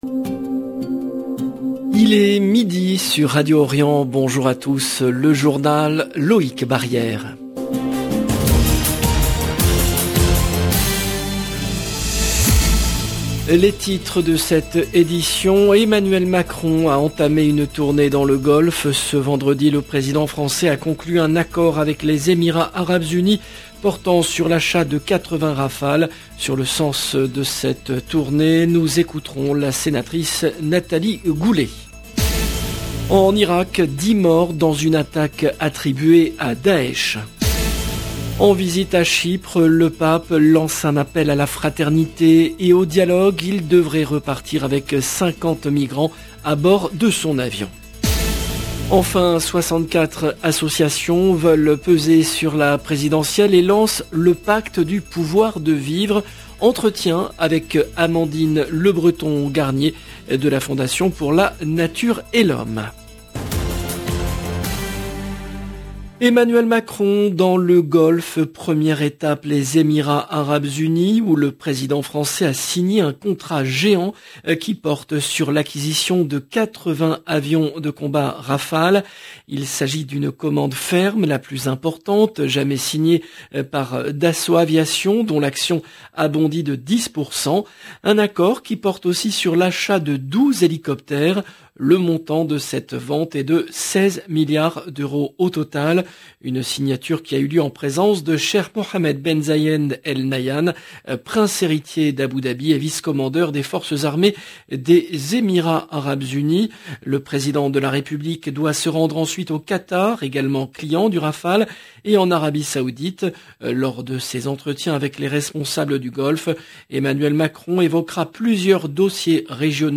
Journal présenté par
Ce vendredi, le président français a conclu un accord avec les Emirats Arabes Unis portant sur l’achat de 80 Rafale. Sur le sens de cette visite dans le Golfe, nous écouterons la sénatrice Nathalie Goulet.